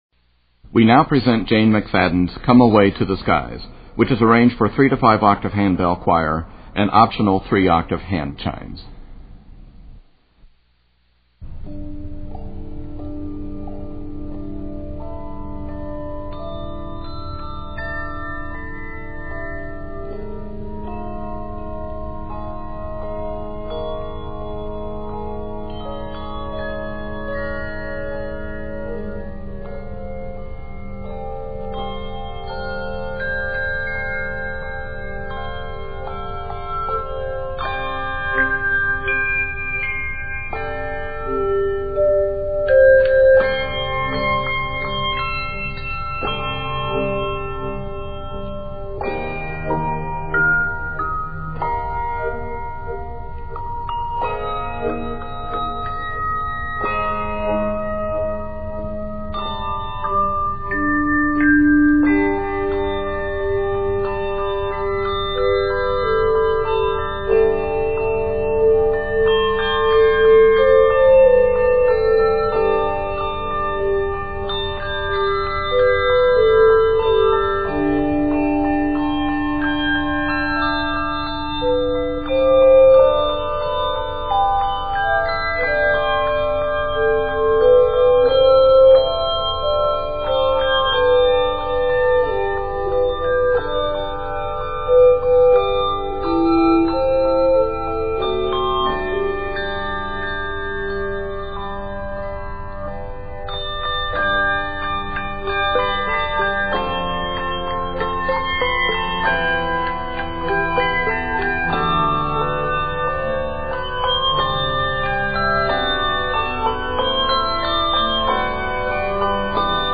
ethereal piece